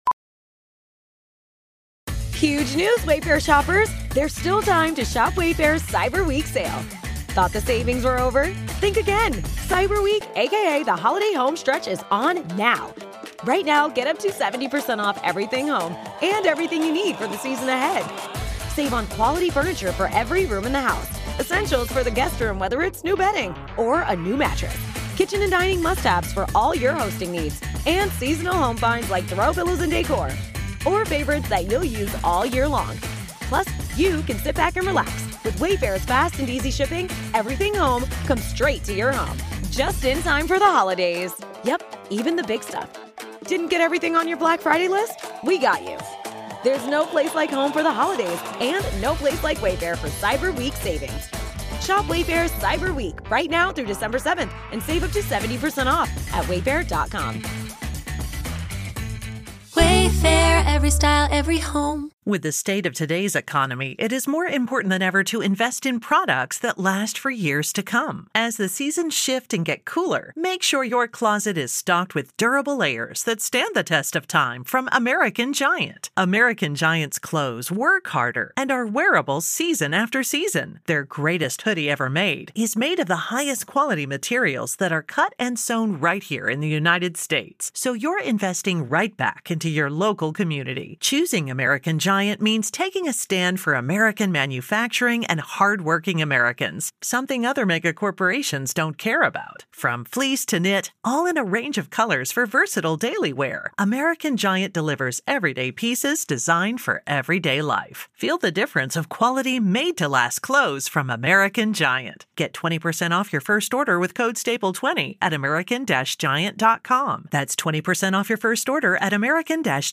Beschreibung vor 7 Monaten Kontrovers, unterhaltsam, meinungsbildend – mit Sky90 präsentiert Sky den umfassendsten Fußball-Live-Talk Deutschlands.